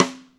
gretsch rimshot f.wav